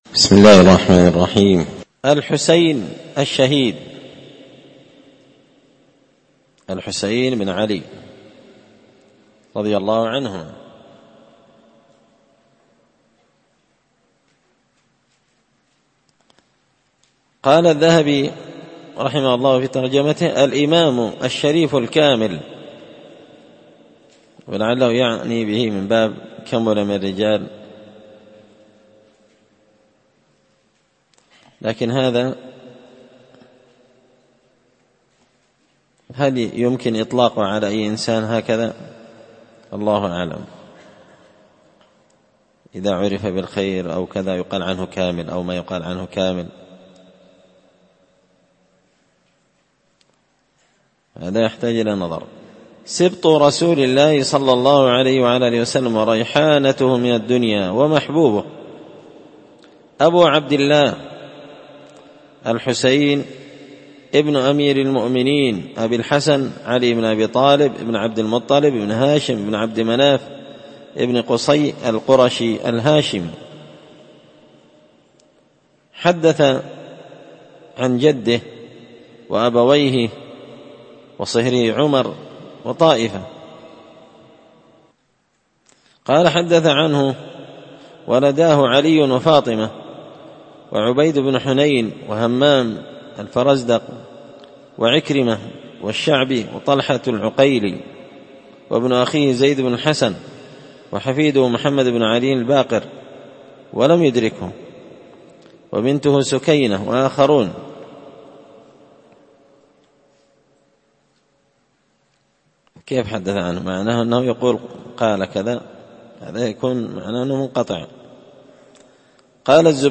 الدرس 200 الحسين بن علي- قراءة تراجم من تهذيب سير أعلام النبلاء